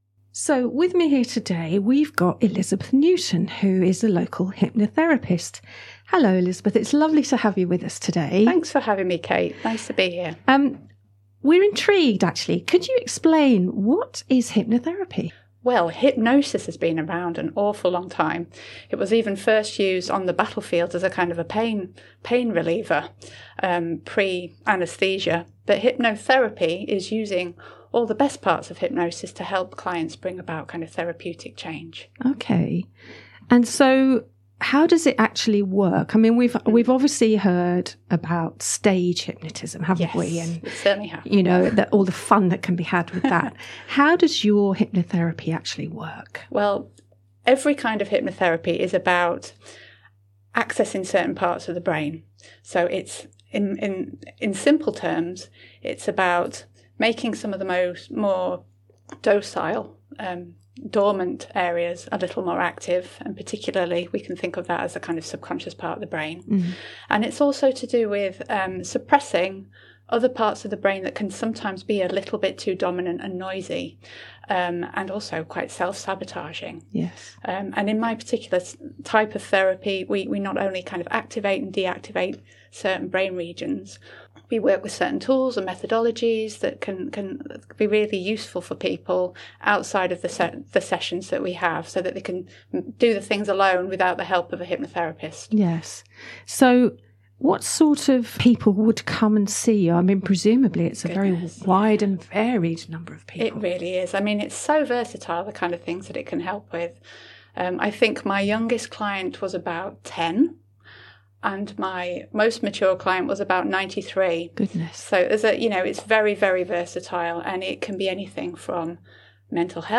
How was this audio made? Lots of my common questions are answered in a recent interview I did for TouchPoint Community Radio based in Stansted, Essex.